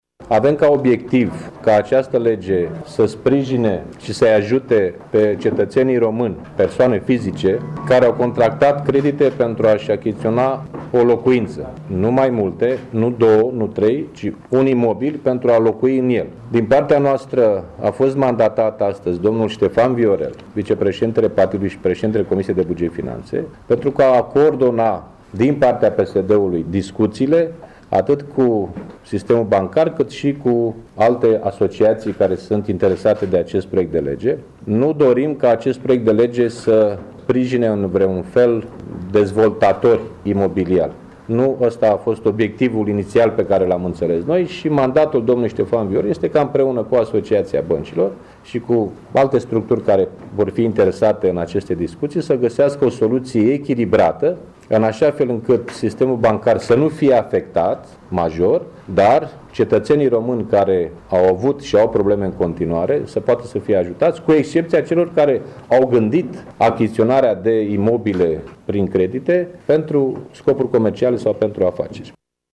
Preşedintele PSD, Liviu Dragnea a declarat vineri, la Braşov, că Legea dării în plată trebuie să sprijine cetăţenii aflaţi în incapacitatea de a-şi plăti creditele luate pentru achiziţionarea unei locuinţe, şi nu dezvoltatorii imobiliari.